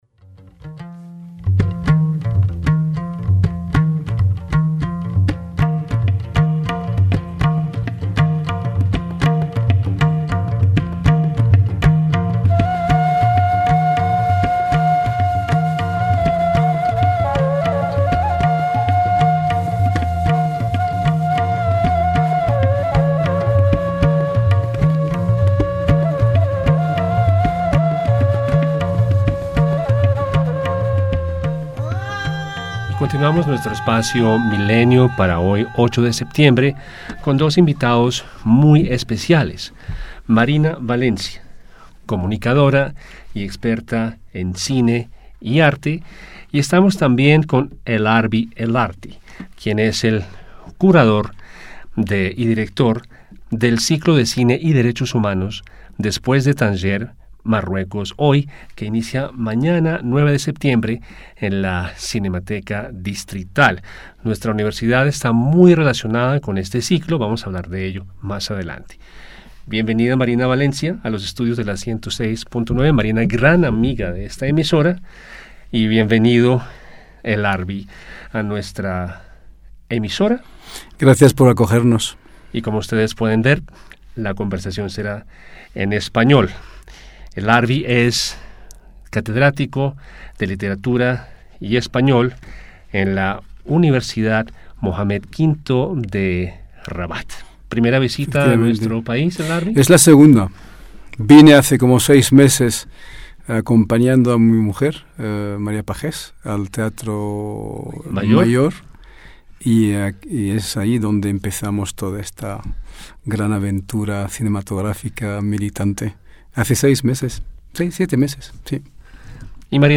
entrevista_cine_marroqui_hjut.mp3